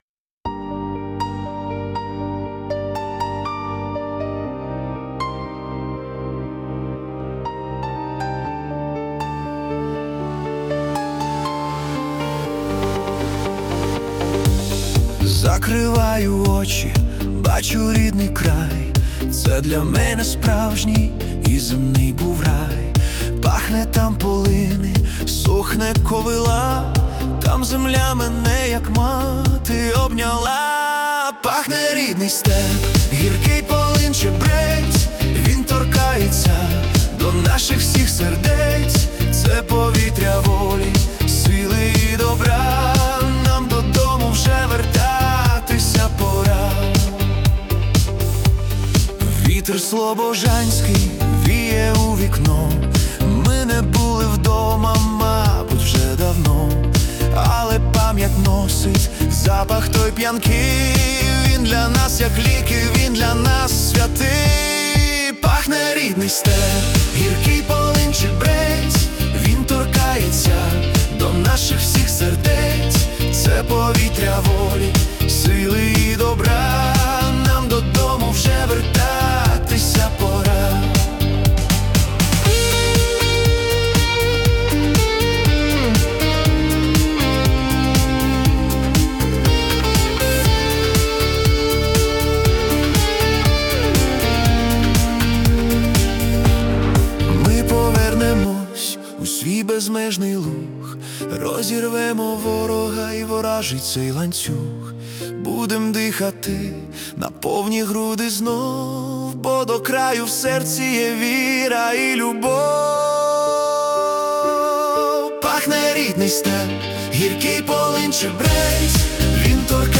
🎵 Жанр: Synth-pop / Upbeat Pop